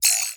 fnl/Assets/Extensions/Advanced_UI/Alerts_Notifications/Computer/Computer Calculation Notificaiton 4.wav